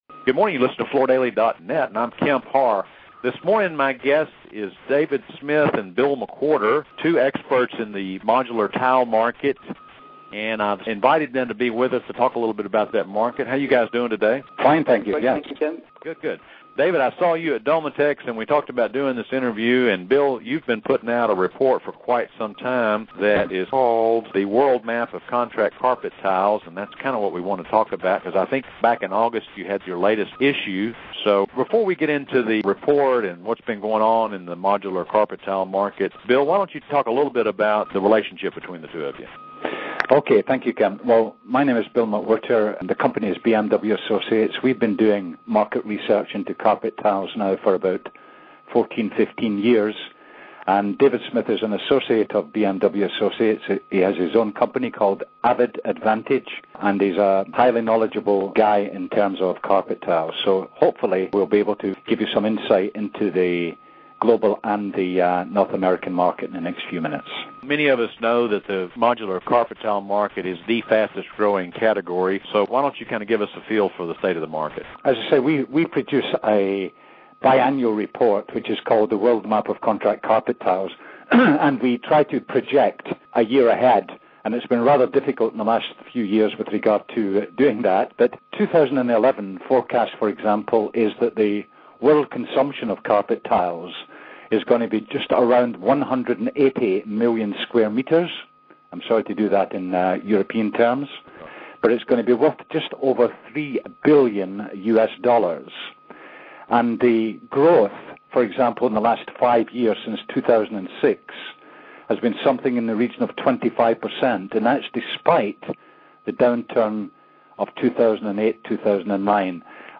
Listen to the interview to hear details on the market size, growth and key share holders.